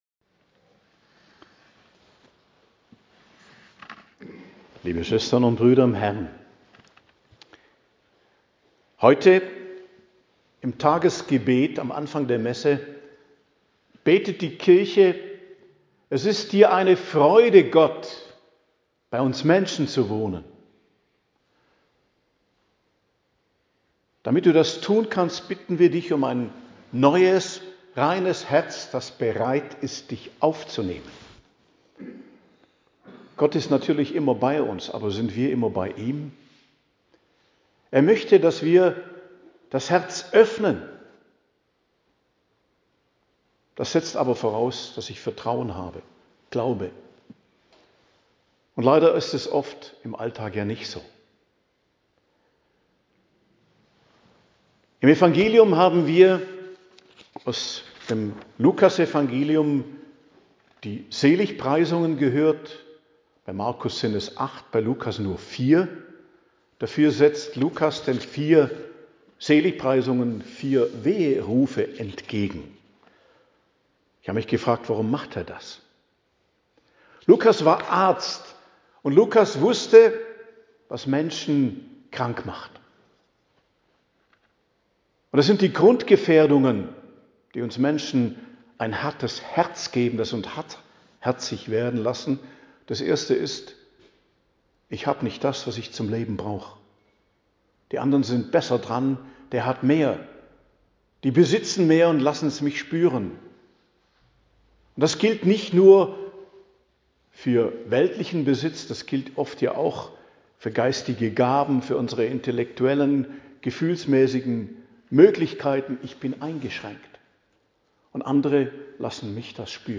Predigt zum 6. Sonntag i.J. am 16.02.2025 ~ Geistliches Zentrum Kloster Heiligkreuztal Podcast